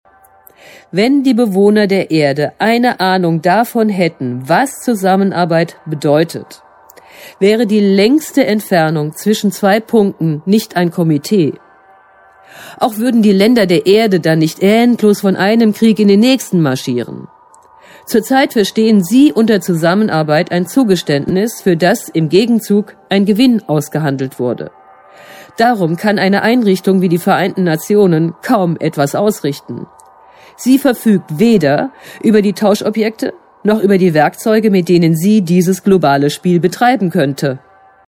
Deutsche Sprecherin Charakteristische, temperamentvolle, expressive und gefühlvolle Stimme Mittlere Stimmlage Stimme auf der CD Katzensehnsucht und CD E.T. 101 DAS KOSMISCHE HANDBUCH ZUR PLANETAREN (R)EVOLUTION
Sprechprobe: Sonstiges (Muttersprache):